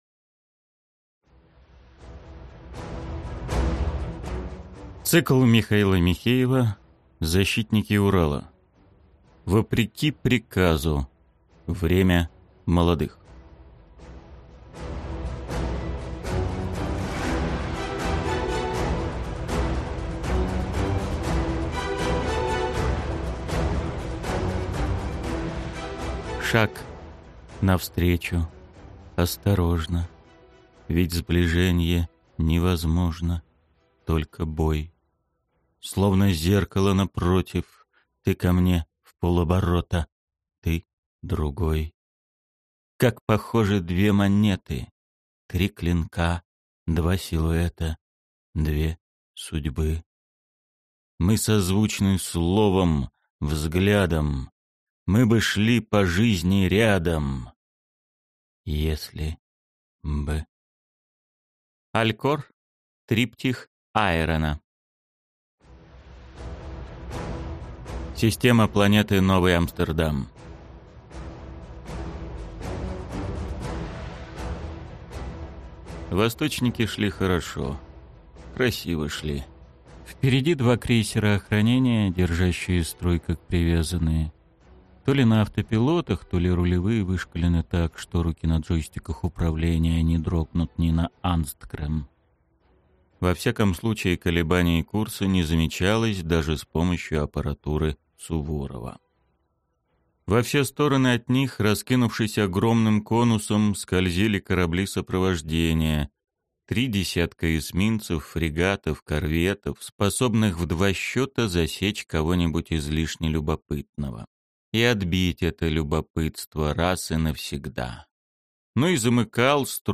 Аудиокнига Время молодых | Библиотека аудиокниг
Прослушать и бесплатно скачать фрагмент аудиокниги